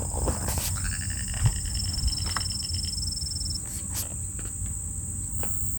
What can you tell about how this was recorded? Country: Argentina Detailed location: Lago Salto Grande Condition: Wild Certainty: Photographed, Recorded vocal